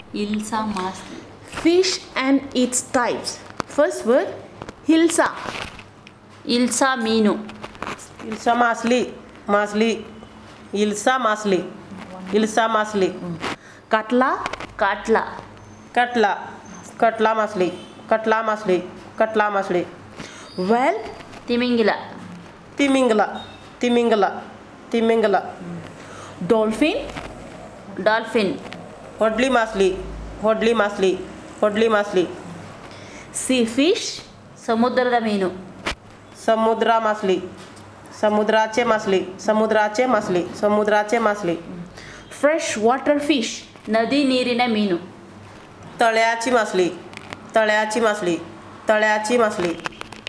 Elicitation of words about Fish and related